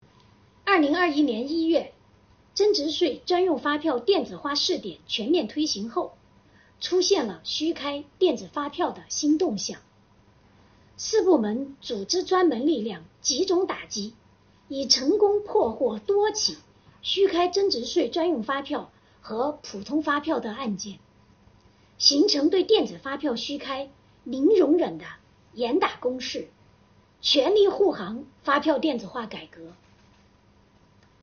7月26日，国家税务总局召开新闻发布会，就税收大数据反映经济发展情况、税务部门学党史办实事扎实推进办税缴费便利化、打击涉税违法犯罪等内容进行发布并回答记者提问。